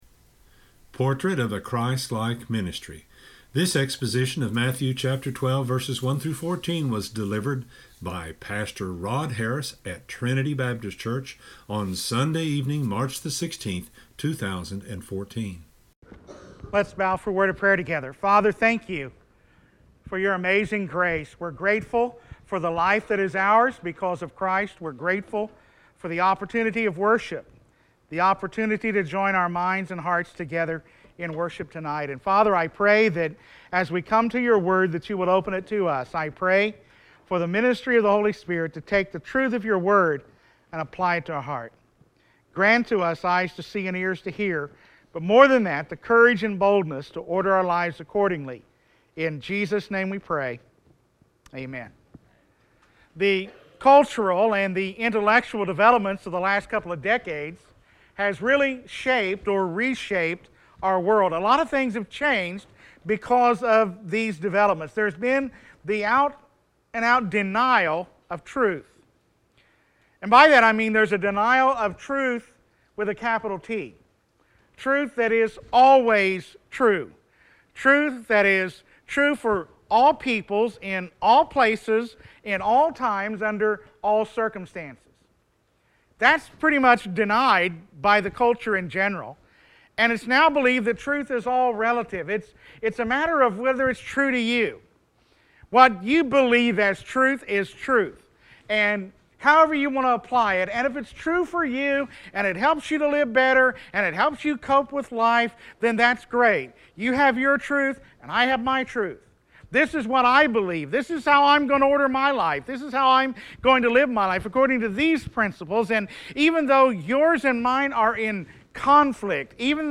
This exposition of Matthew 12:1-14